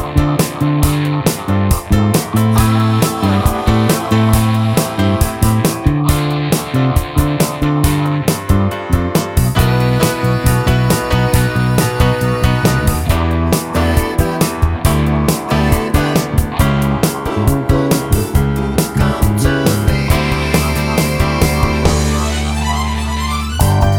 Backing Vocals At Half Volume Pop (1970s) 4:30 Buy £1.50